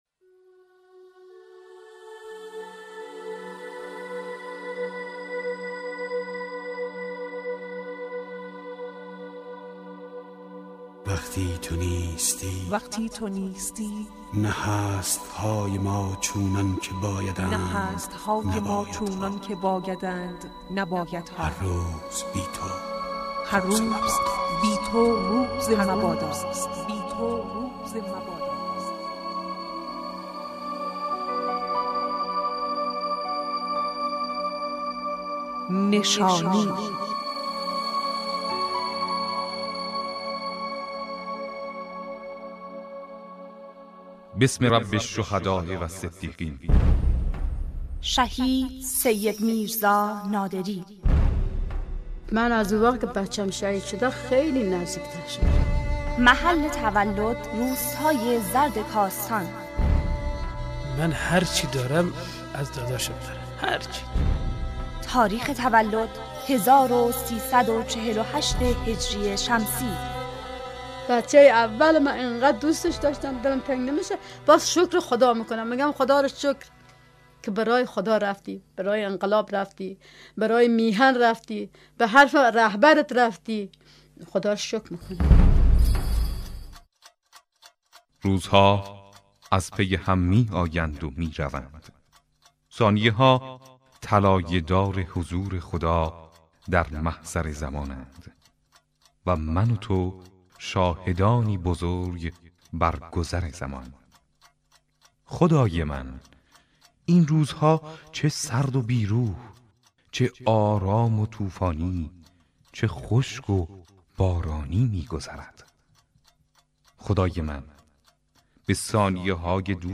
صوت مصاحبه